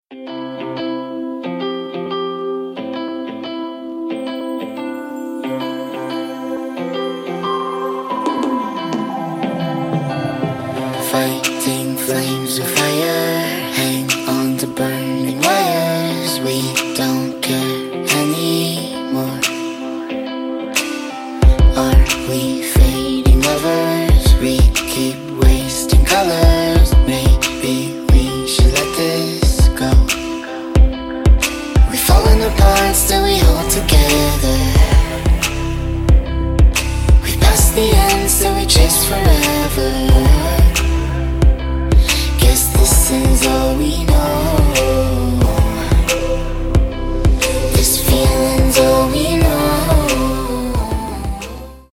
Romantc music